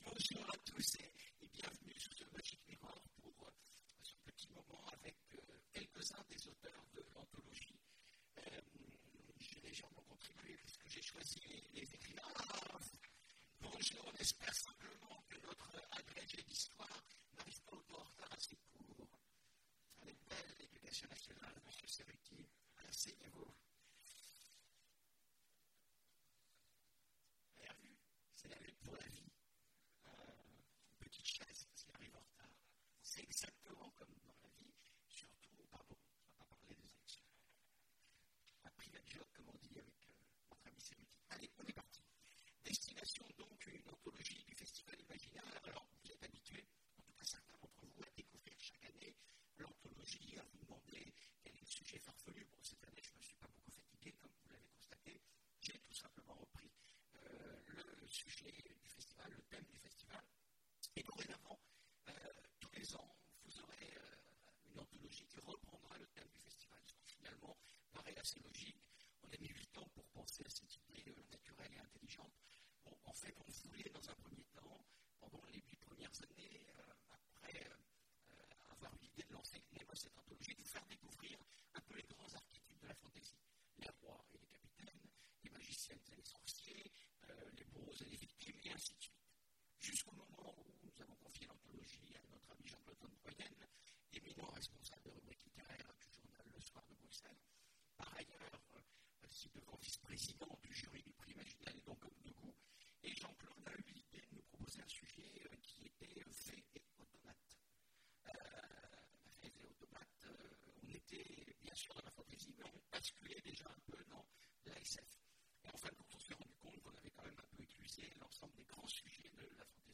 Imaginales 2017 : Conférence Destinations, l'anthologie du festival !